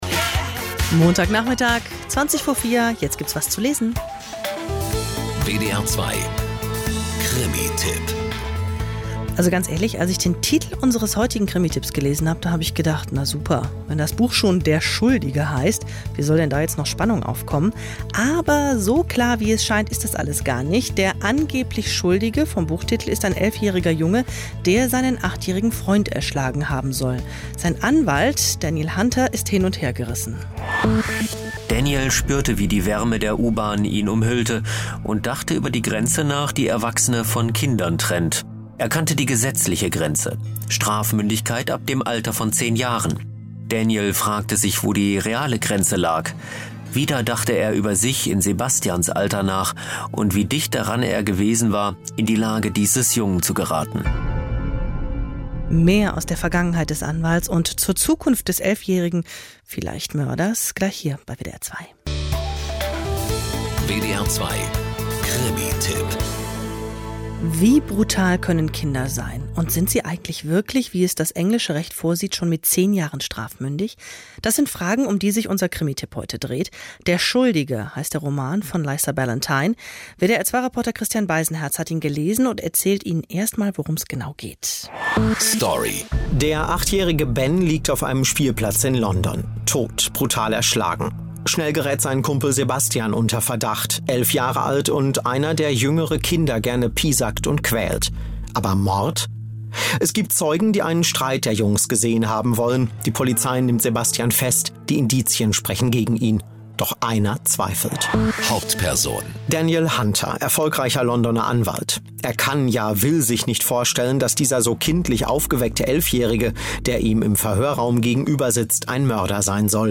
Aber der Reihe nach – ich hab das für WDR 2 mal in drei Minuten zusammengefasst: